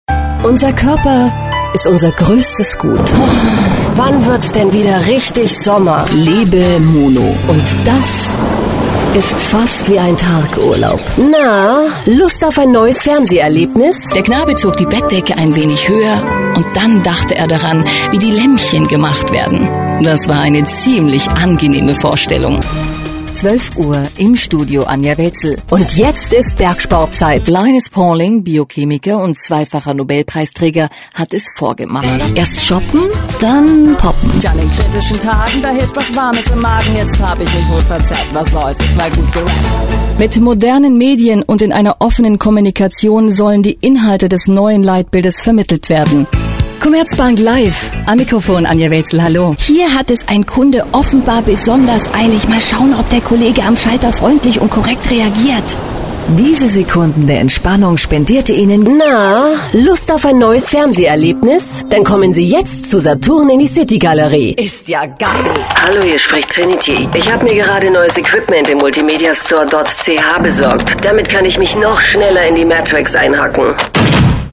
Kein Dialekt
Sprechprobe: Industrie (Muttersprache):
german female voice over artist.